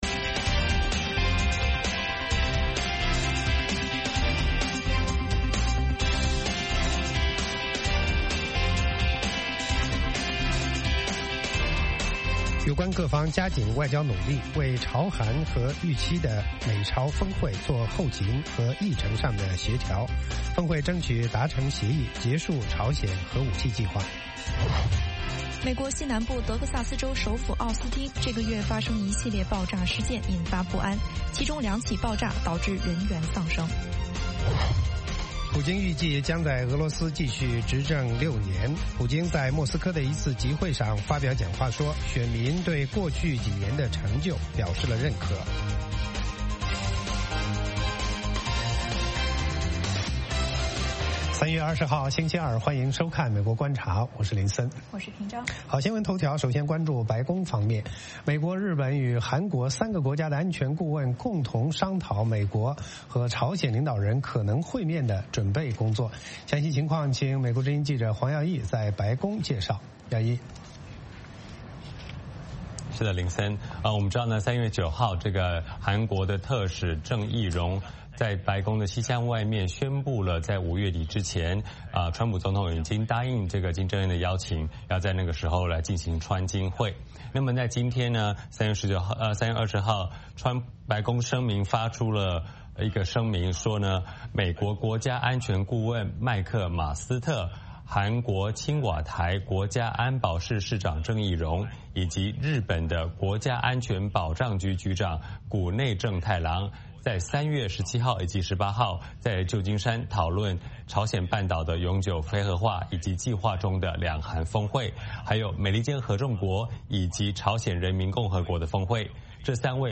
“VOA卫视 美国观察”掌握美国最重要的消息，深入解读美国选举，政治，经济，外交，人文，美中关系等全方位话题。节目邀请重量级嘉宾参与讨论。